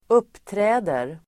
Uttal: [²'up:trä:der]